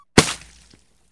slime.ogg